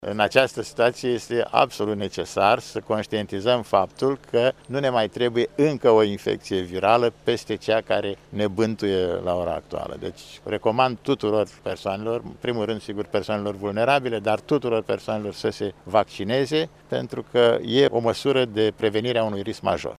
În actualul context epidemiologic este necesară vaccinarea antigripală a populaţiei, a subliniat astăzi, într-o conferinţă de presă, directorul DSP Iaşi, Vasile Cepoi.